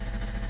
tbd-station-14/Resources/Audio/Effects/Footsteps/borgwalk4.ogg
borgwalk4.ogg